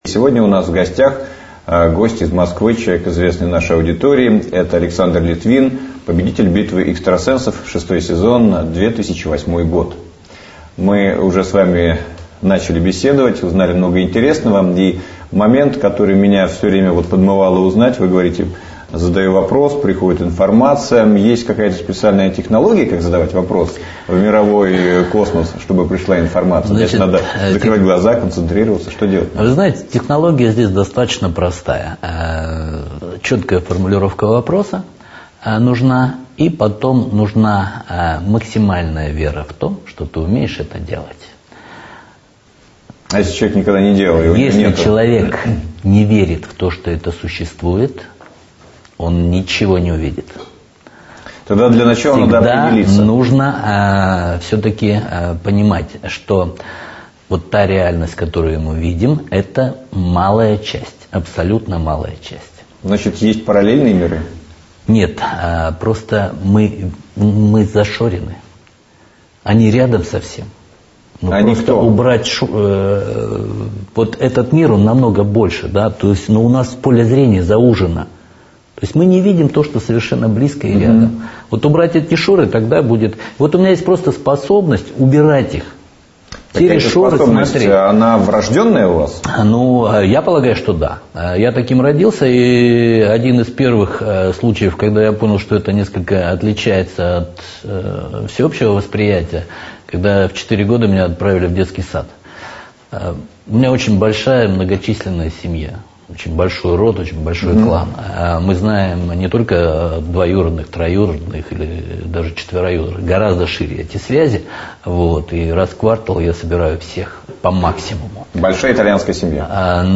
Аудиокнига : Экстрасенсы